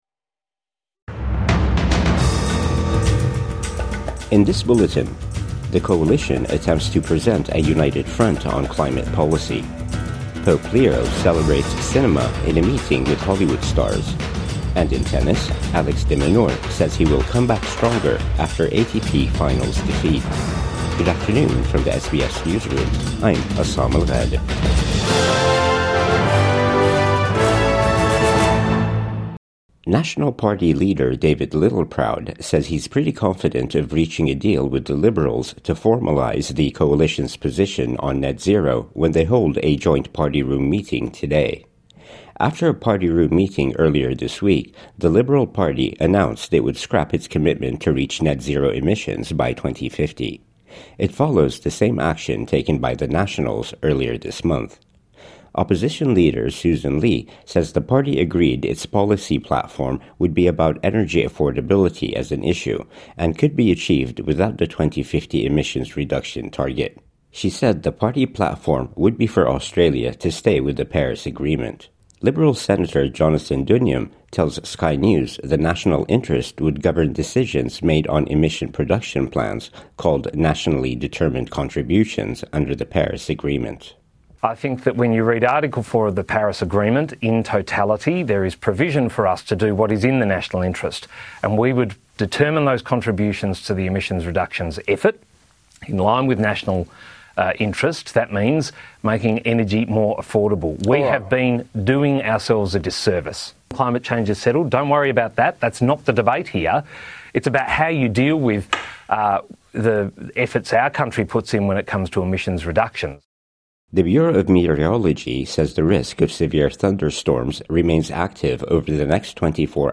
Coalition attempts to present united front on climate policy | Midday News Bulletin 16 November 2025